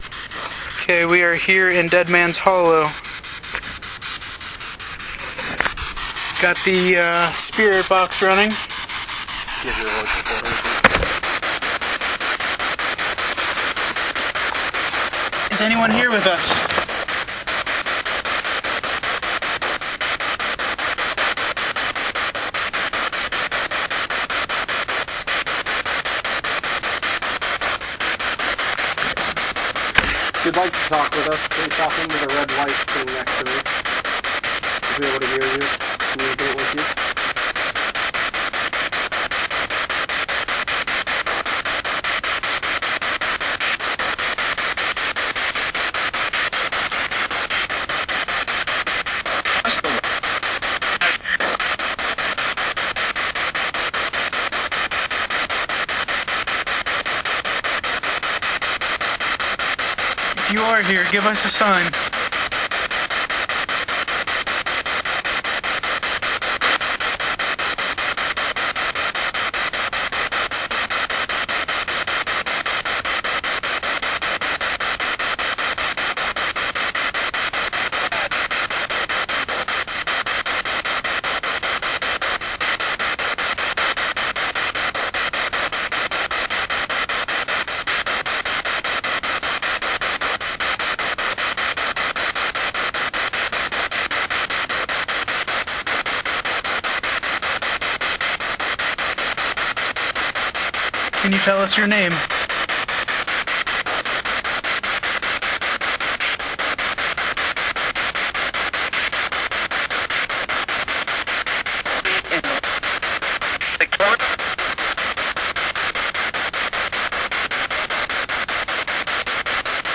McKeesport, PA
We managed to capture a little evidence utilizing our spirit box and recorder.
Abandoned Mill (With Spirit Box)
• Same time – Odd Noises
Abandoned-Ruins.mp3